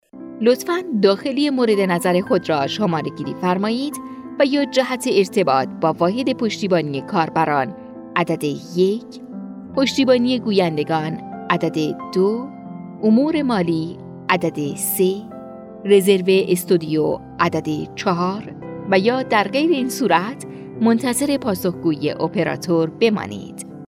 Female
Young
IVR